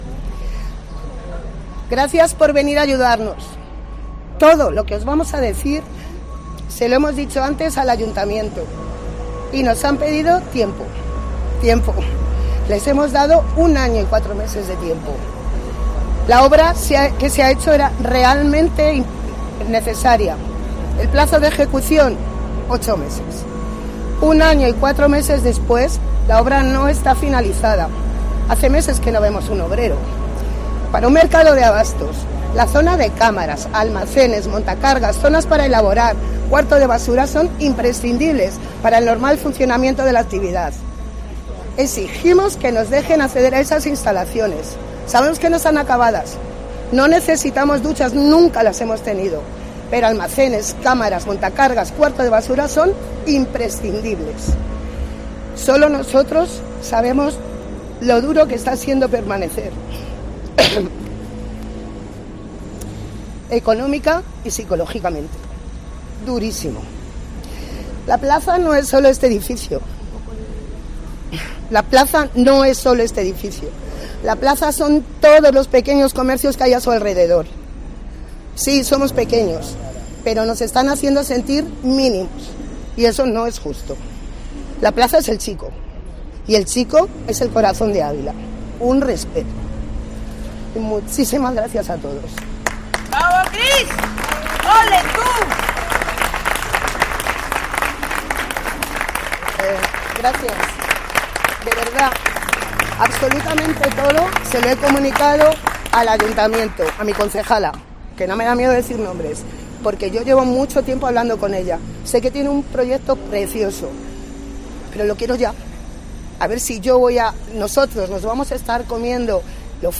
AUIDO/ Lectura del Manifiesto--mercado-abastos de Ávila